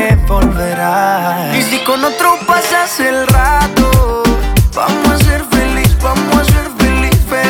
• Urbano latino